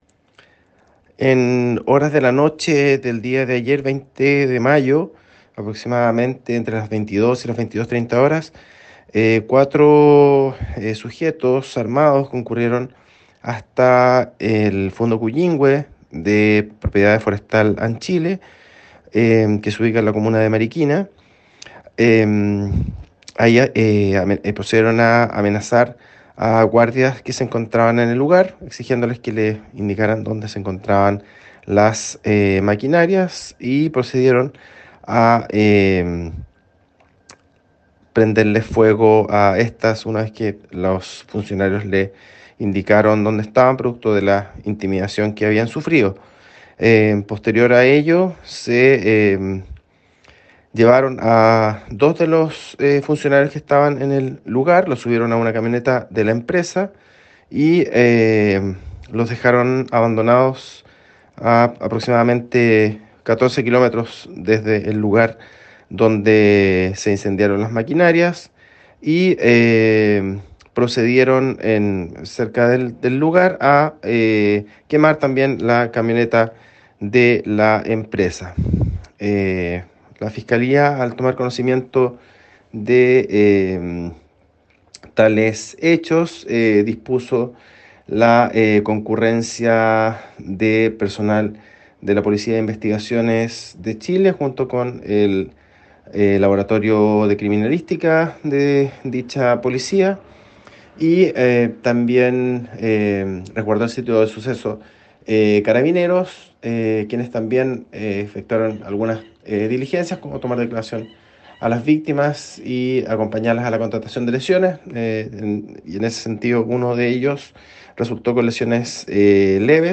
fiscal subrogante Carlos Silva sobre este hecho: